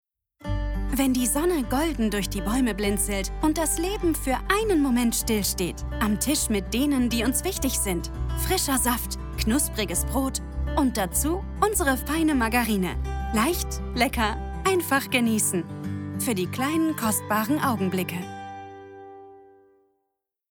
Jong, Natuurlijk, Speels, Toegankelijk, Commercieel
Commercieel
Her voice is young, fresh, and energetic — perfectly suited to bring any project to life and deliver messages with authenticity and impact.